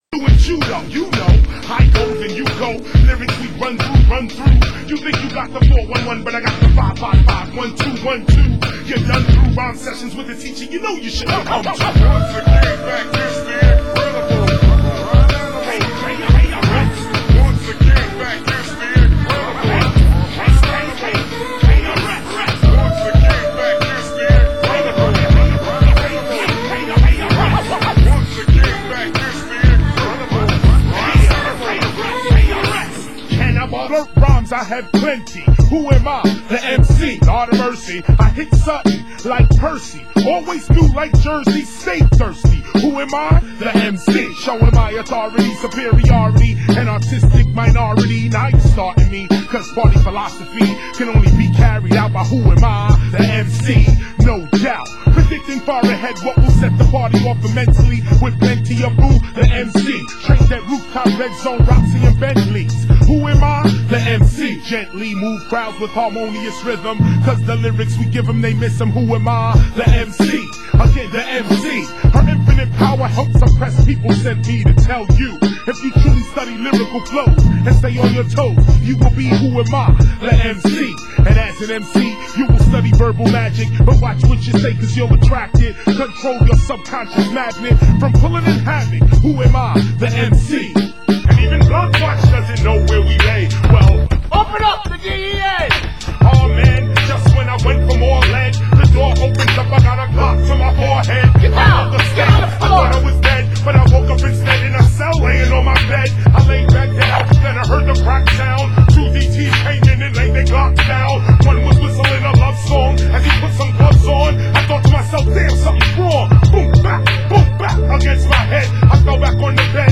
Format: Vinyl 12 Inch
Genre: Hip Hop